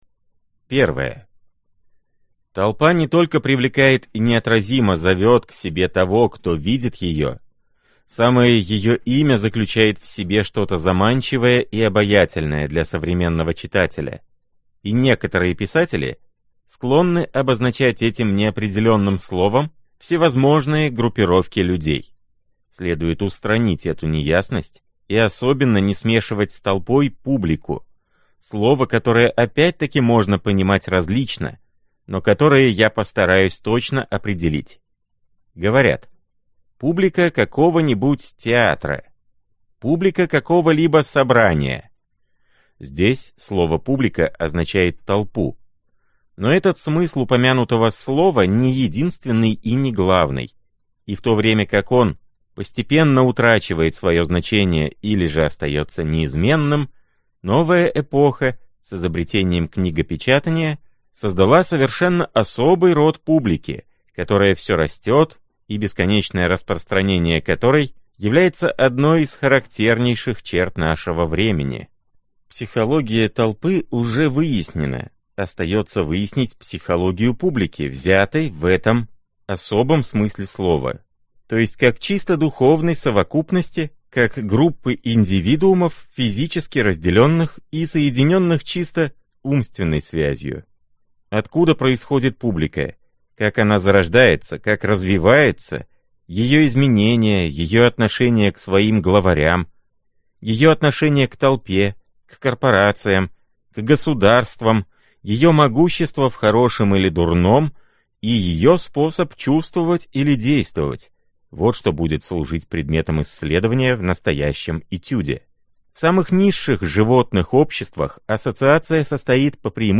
Аудиокнига Сознание масс | Библиотека аудиокниг